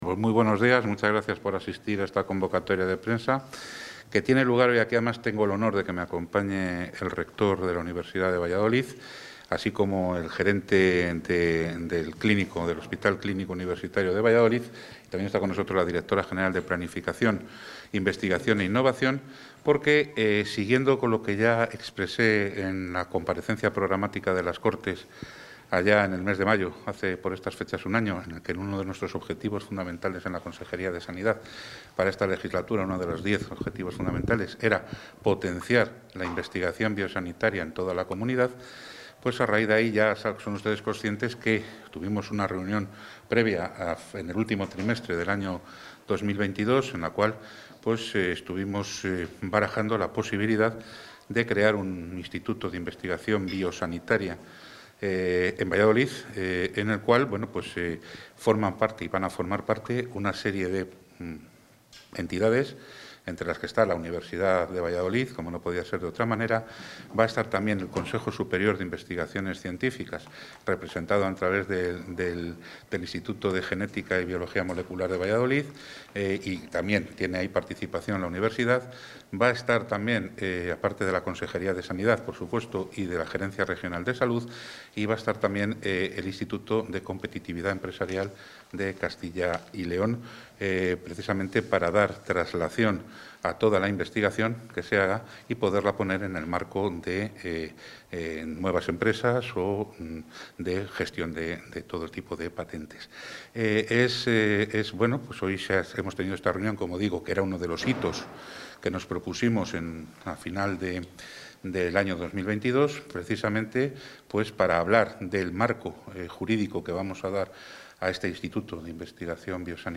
Material audiovisual con las declaraciones del consejero de Sanidad en materia de investigación biosanitaria